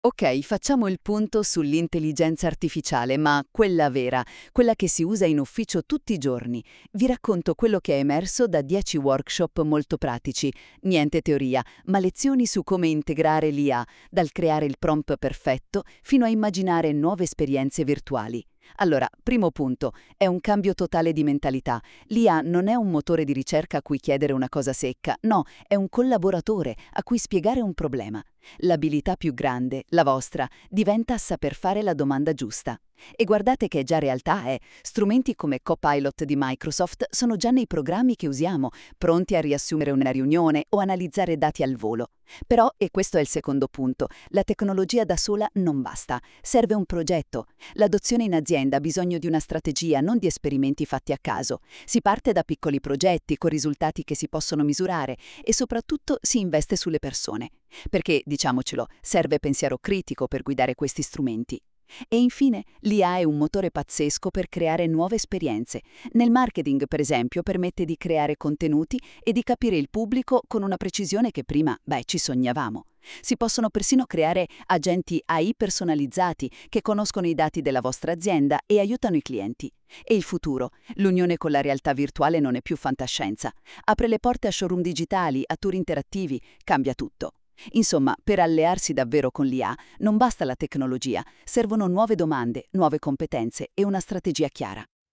SCARICA QUI IL PODCAST DI RESTITUZIONE CREATO CON L'AI